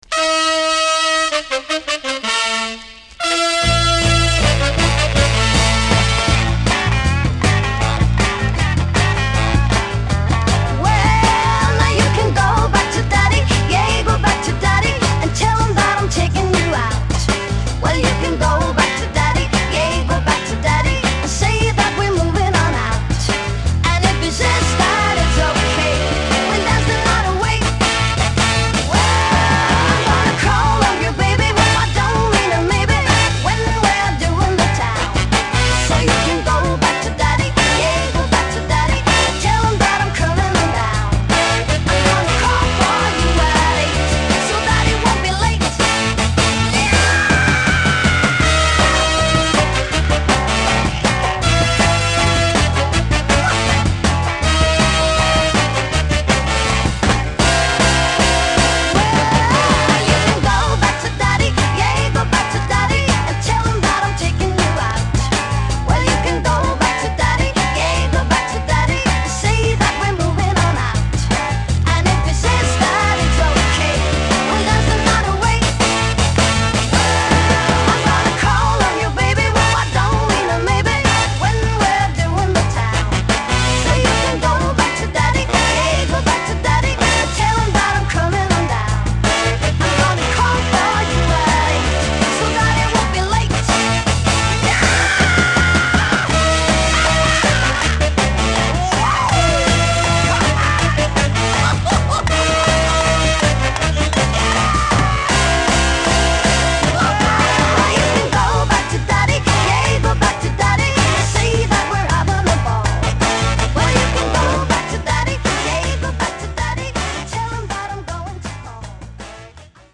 シャープなブラスと共に疾走するFUNKYチューン